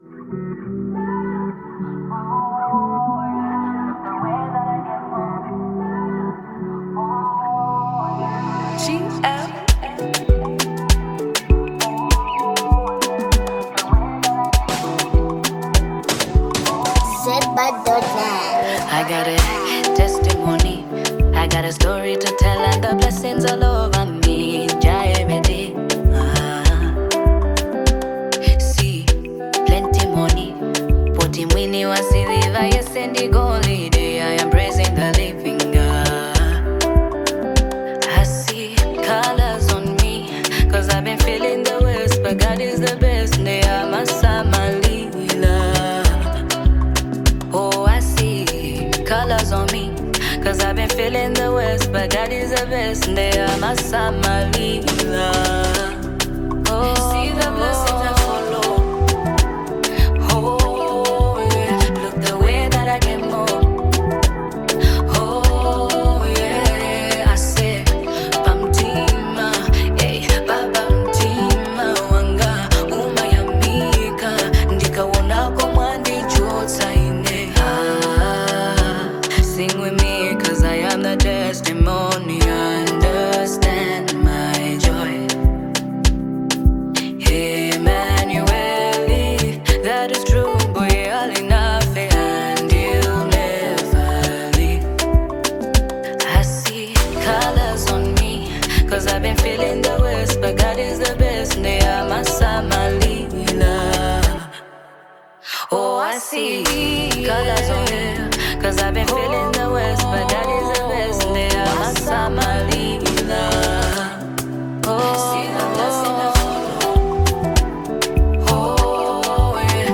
Category: Gospel Music